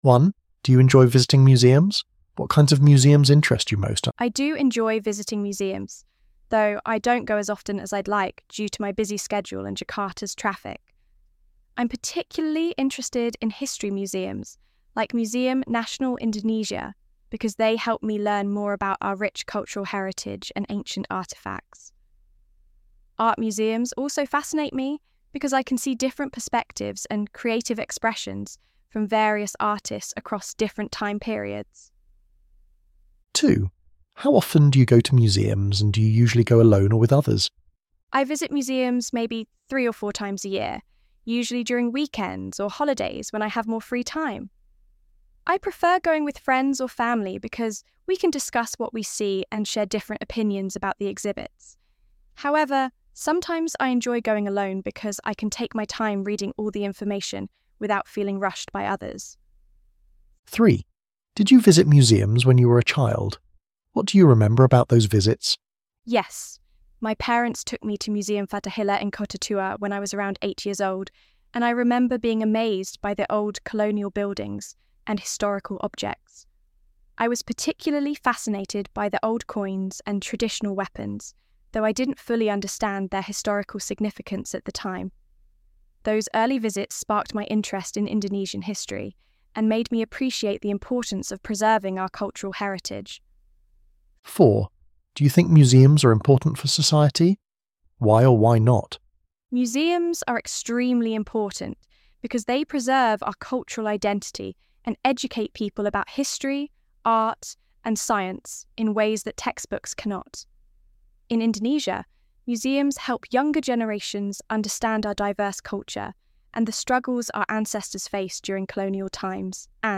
ElevenLabs_13_September.mp3